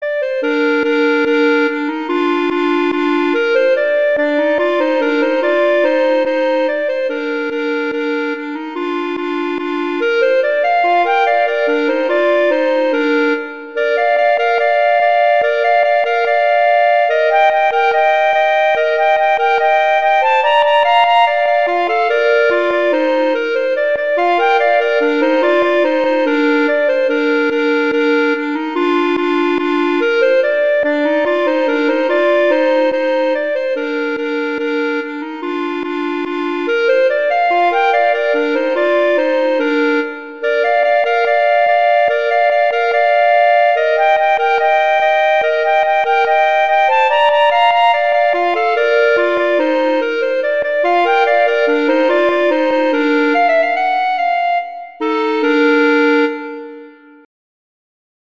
Instrumentation: two clarinets
arrangements for two clarinets